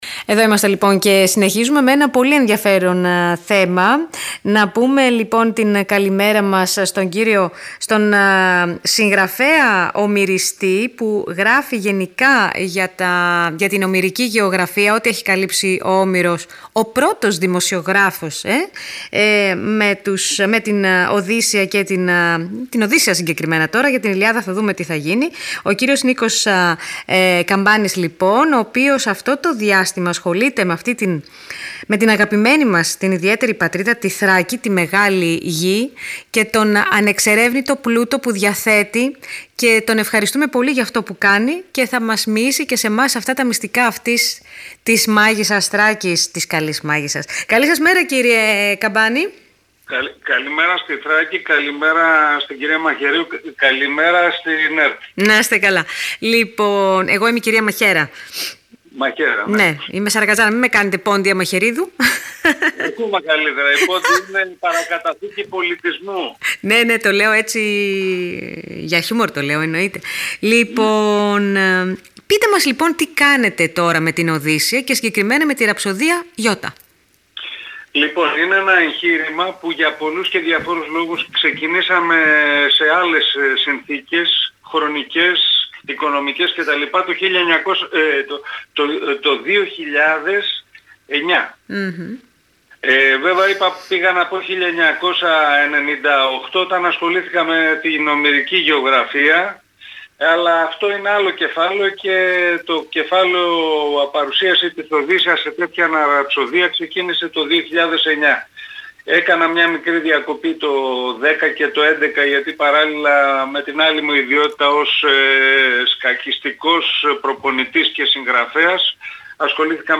Ραψωδία ι΄ - Ραδιοφωνική συνέντευξη